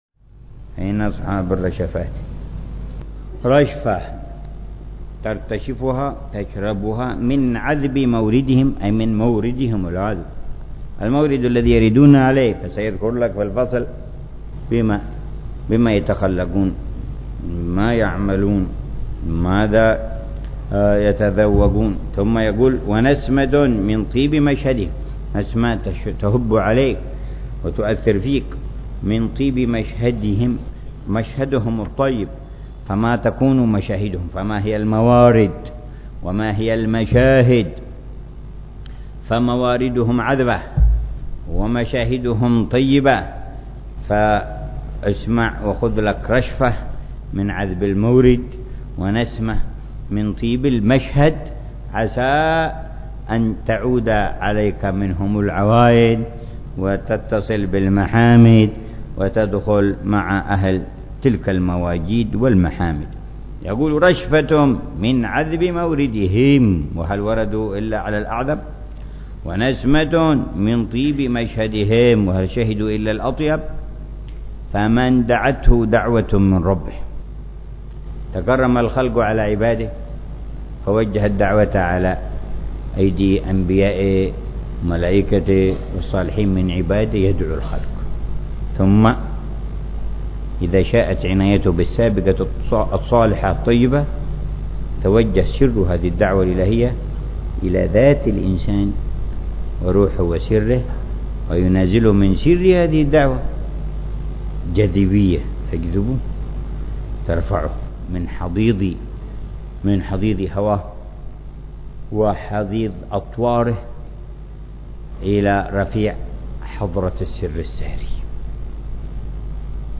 شرح الحبيب عمر بن محمد بن حفيظ لرشفات أهل الكمال ونسمات أهل الوصال. تأليف السيد العلامة الإمام/ عبد الرحمن بن عبد الله بن أحمد بلفقيه (1089-1162هـ) بدار المصطفى ضمن دروس الدورة الصيفية الحادية والعشرين في شهر رمضان المبارك من العام 1436هـ.